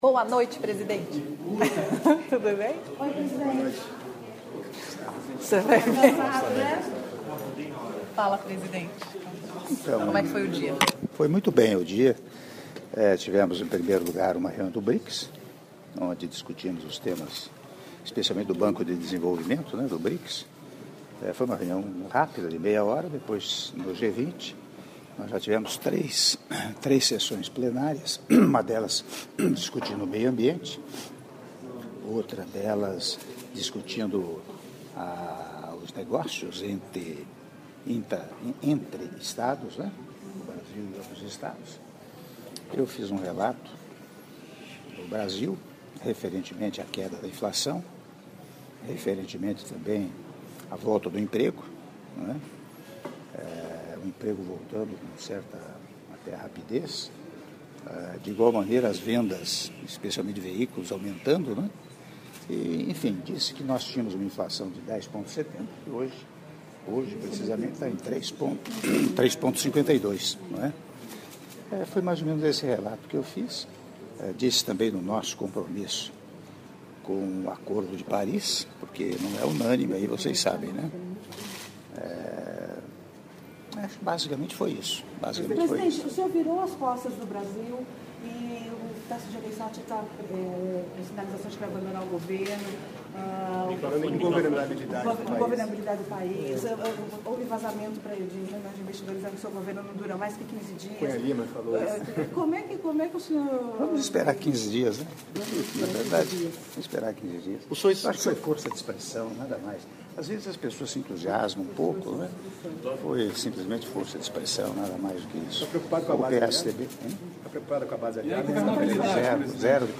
Áudio da entrevista coletiva concedida pelo Presidente da República, Michel Temer, na chegada ao Hotel Le Méridien após concerto na Filarmônica de Hamburgo - (04min05s) - Hamburgo/Alemanha